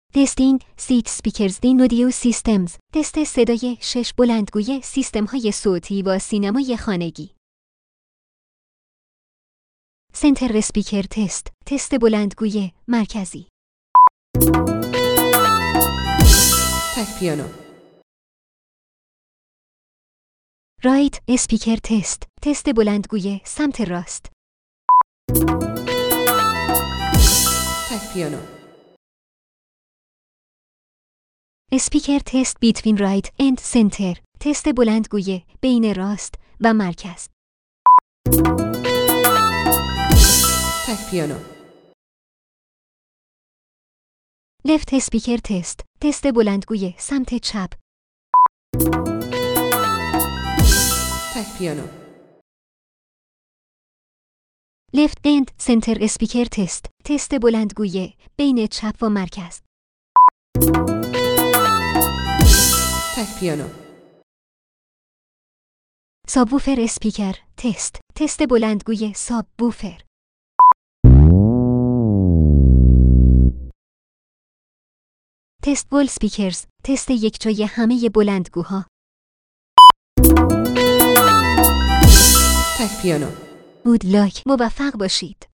تست صدای سیستم صوتی code 150 - تک پیانو
دانلود رایگان تست صدای استریو
فایل صوتی تست صدای سیستم صوتی را از طریق فلش ممری در دستگاه صوتی پخش کنید در این فایل صوتیف تمامی 6 بلندگو متصل به دستگاه را تست صدا خواهد کرد و شما متوجه درستی نصب یا کار کردن بلندگوها خواهید شد.
تست بلندگوی مرکزی
تست بلندگوی سمت راست
بلندگوی سمت راست و مرکز
بلندگوی سمت چپ
بلندگوی سمت چپ و مرکز
تست ساب ووفر
تست همه بلندگوها یکجا